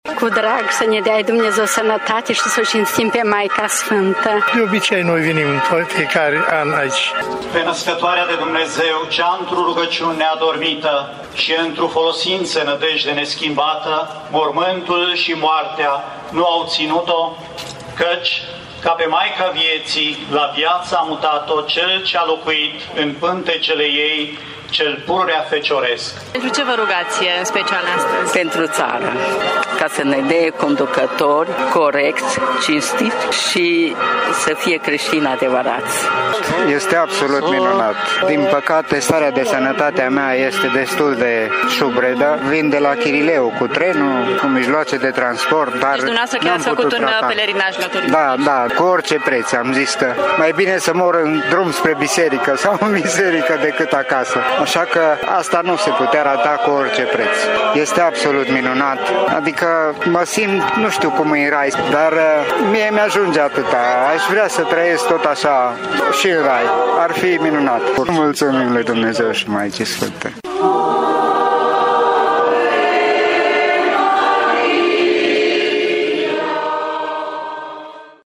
Mulți dintre credincioși s-au îndreptat miercuri către sanctuarele Mariei din toată țara, un astfel de loc fiind și la Tîrgu-Mureș, în parohia greco-catolică cu hramul ”Adormirea Maicii Domnului” din cartierul Tudor.
A avut loc și o procesiune cu statuia Fecioarei Maria, iar oamenii au cântat și s-au rugat pentru familii și pentru țară.